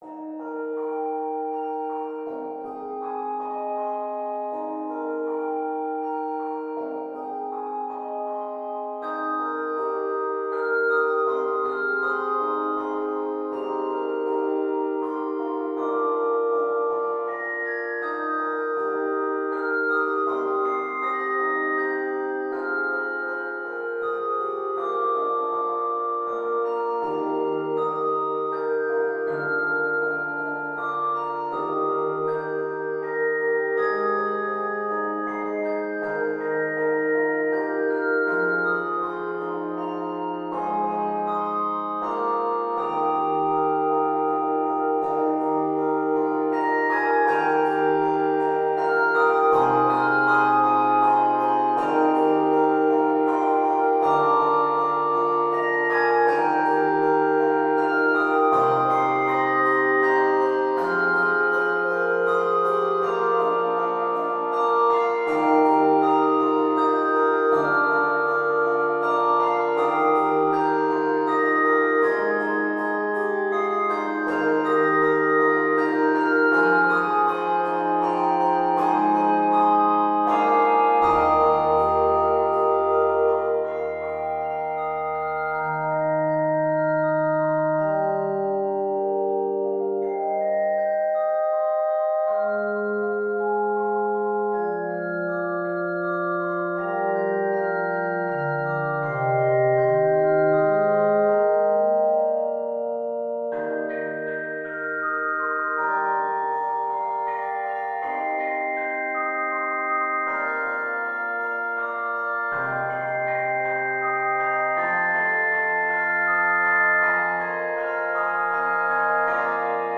Key of Eb Major. 113 measures.